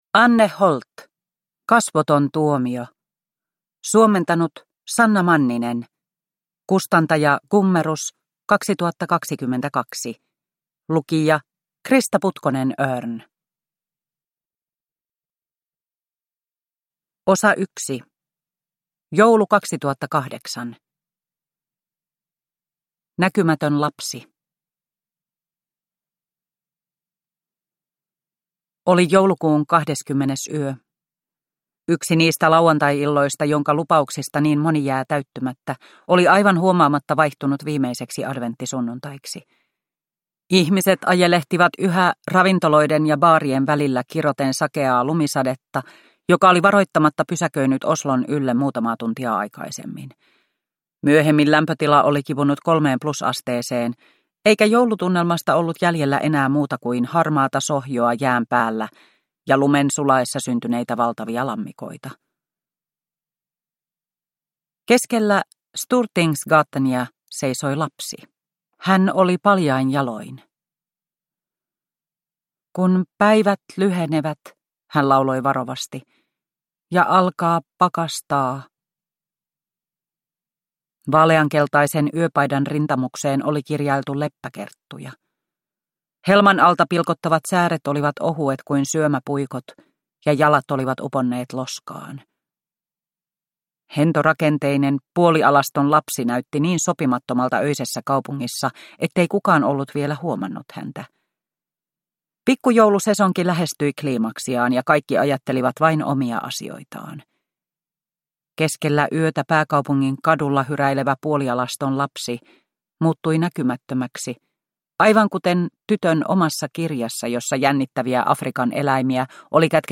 Kasvoton tuomio – Ljudbok – Laddas ner
Produkttyp: Digitala böcker